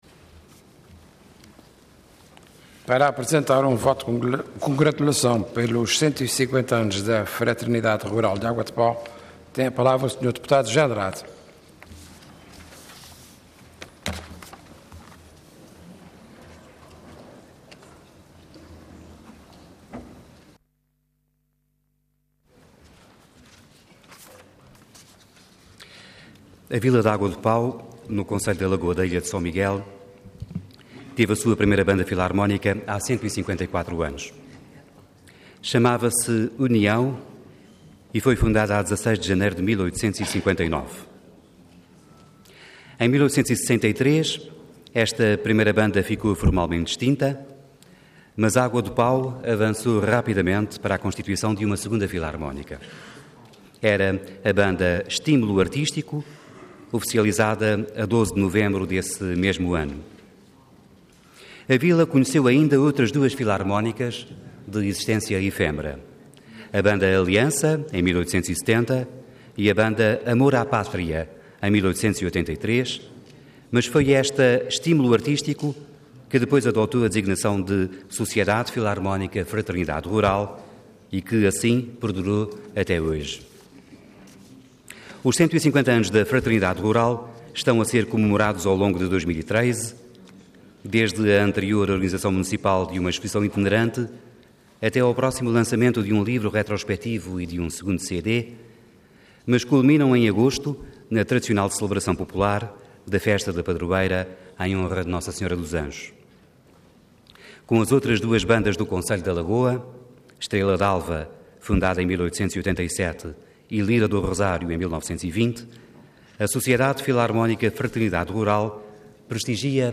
Intervenção Voto de Congratulação Orador José Andrade Cargo Deputado Entidade PSD